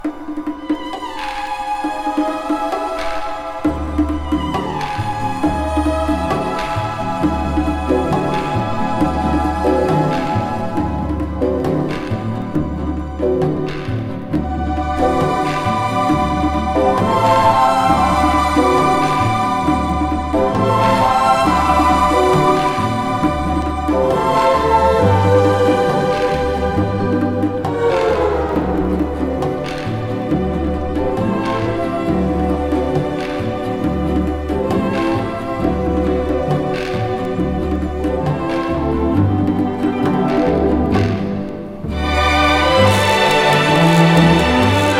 本盤では、エキゾティックさを帯びた楽曲を取り上げた1枚。
新たな息吹を与えられたスタンダード楽曲の数々が豊かに味わい深く。
Jazz, Pop, Easy Listening　USA　12inchレコード　33rpm　Mono